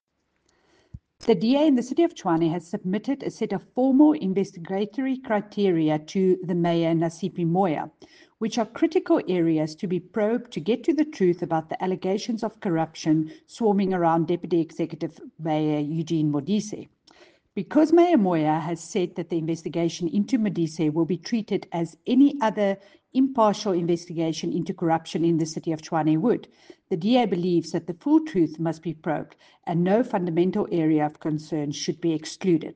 Note to Editors: Please find an English soundbite by Cllr Jacqui Uys